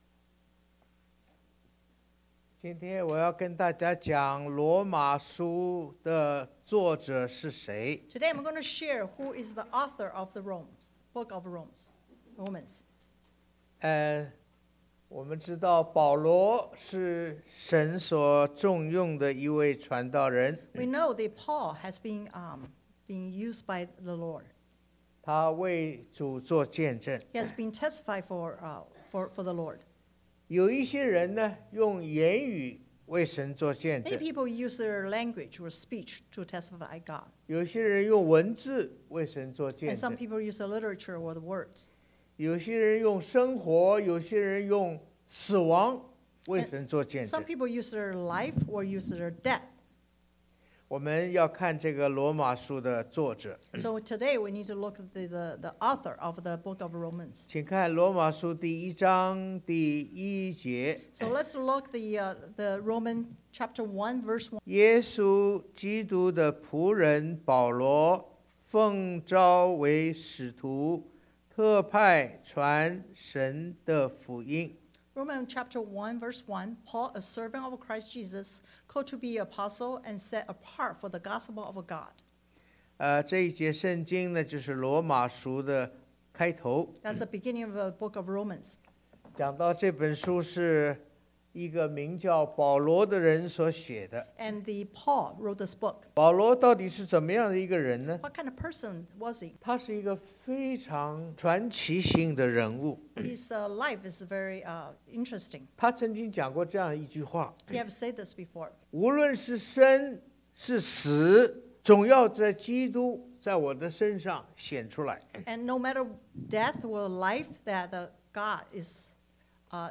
Romans 1:1 Service Type: Sunday AM Bible Text